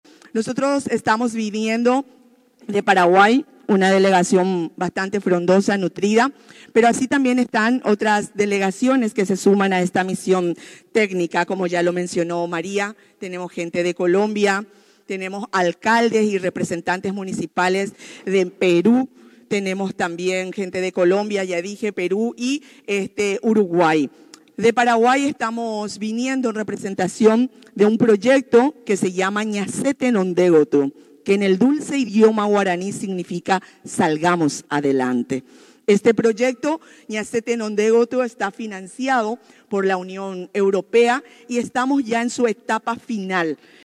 CAROLINA-ARANDA-INTENDENTE-URUGUAY.mp3